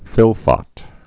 (fĭlfŏt)